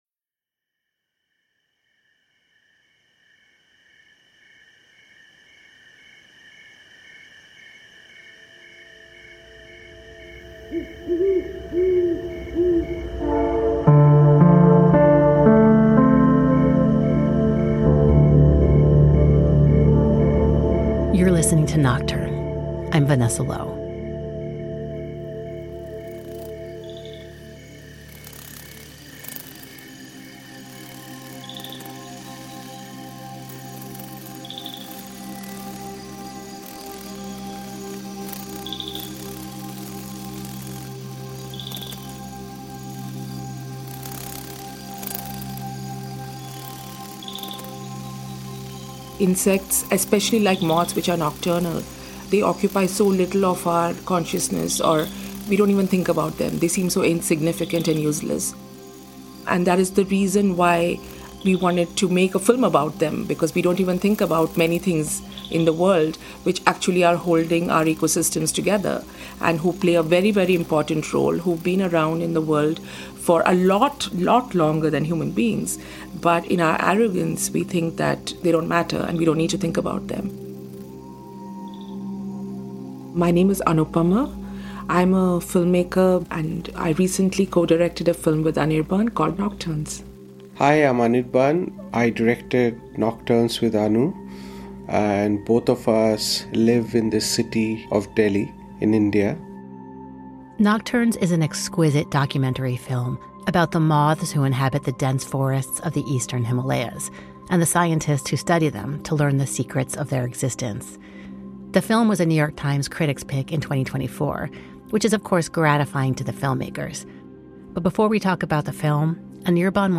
All natural sounds in this episode were original to the film